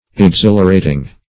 exhilarating - definition of exhilarating - synonyms, pronunciation, spelling from Free Dictionary
Exhilarating \Ex*hil"a*ra`ting\, a.